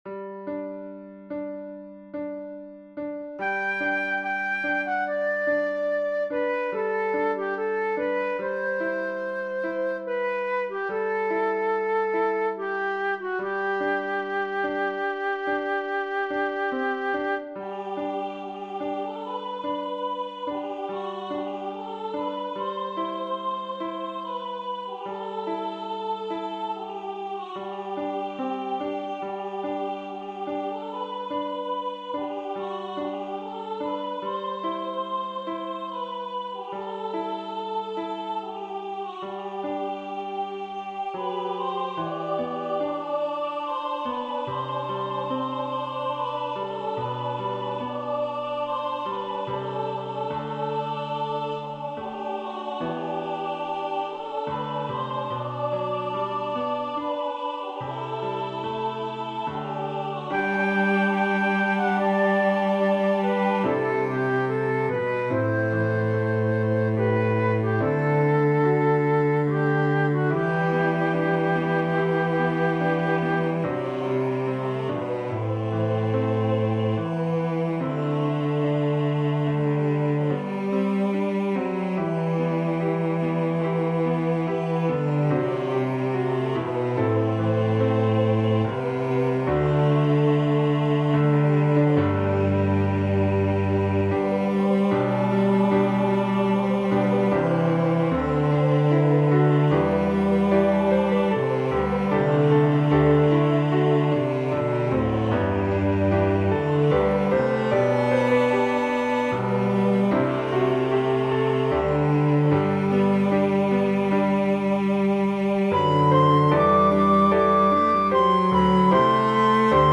SAB with Cello, Flute, & Piano Accompaniment Hymn #30.